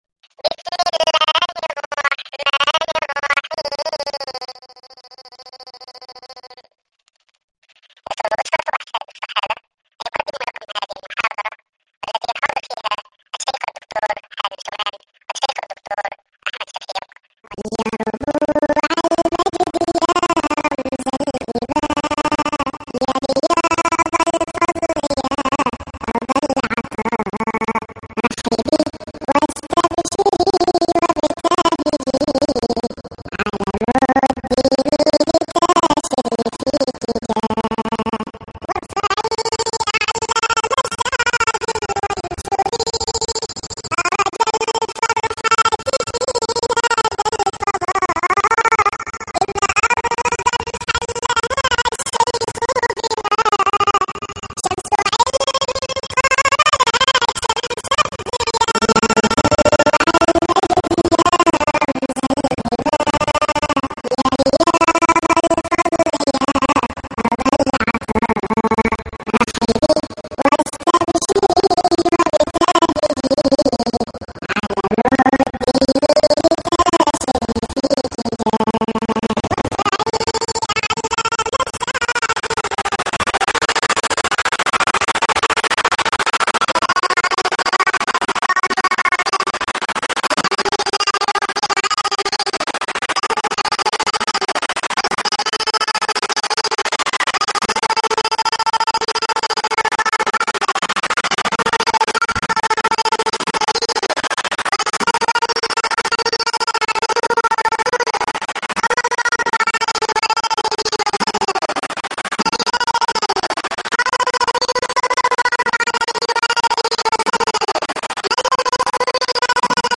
درس في جامعة الزقازيق